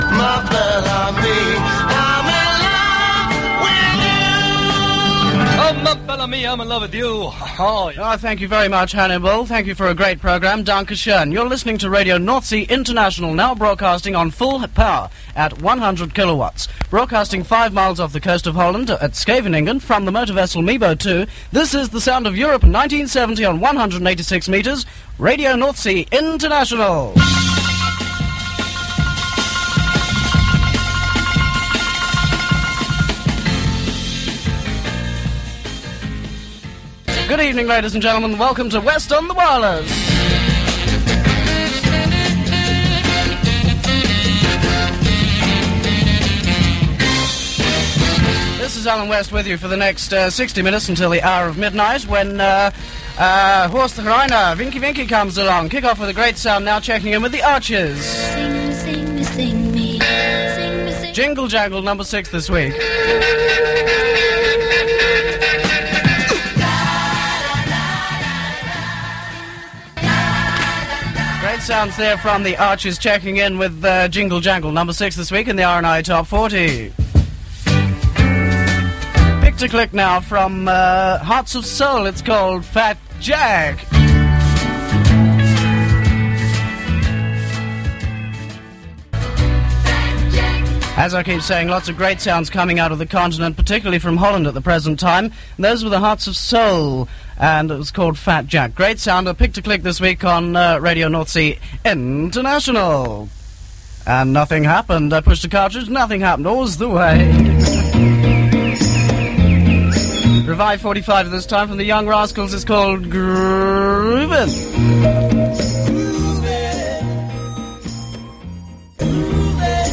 So here again is “the sound of a young Europe” in crisp FM quality.